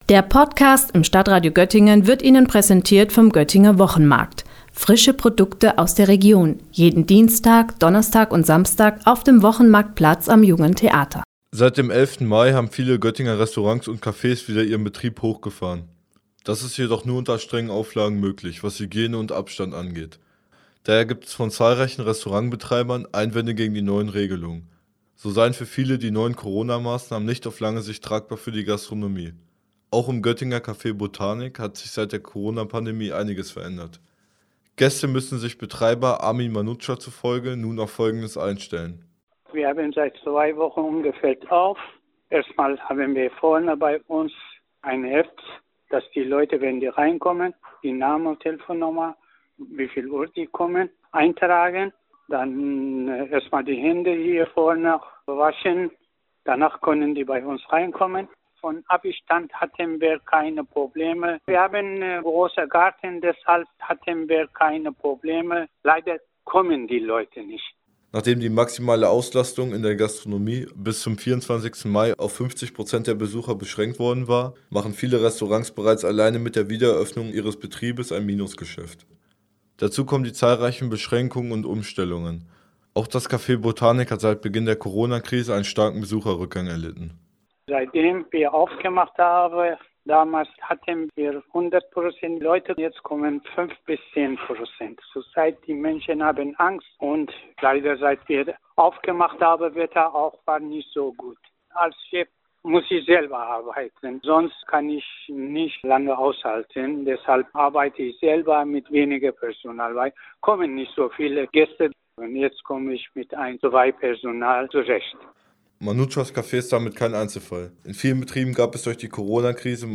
O-Ton 1